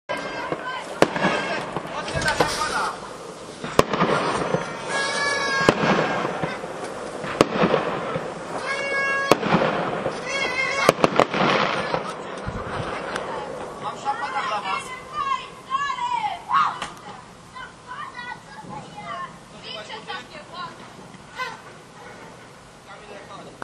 Sounds of the northwest suburbs in the seconds after midnight, New Year’s Day 2025.